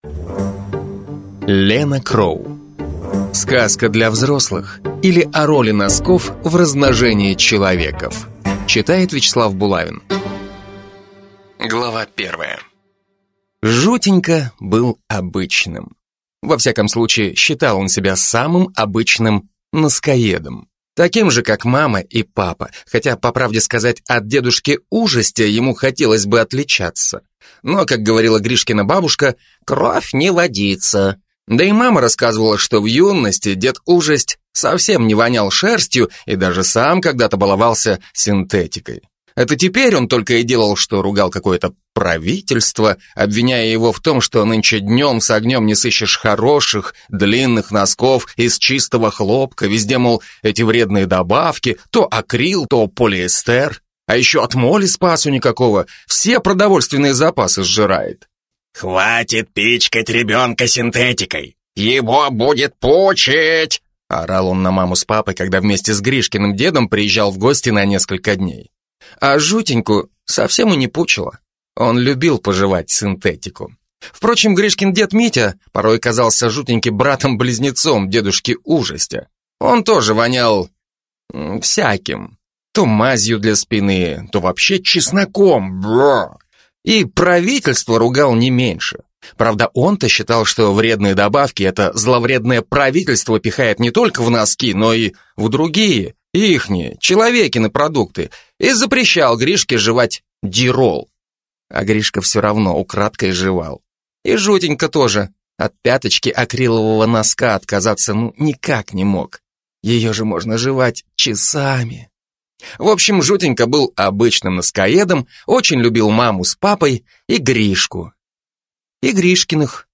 Аудиокнига Сказка для взрослых, или О роли носков в размножении человеков | Библиотека аудиокниг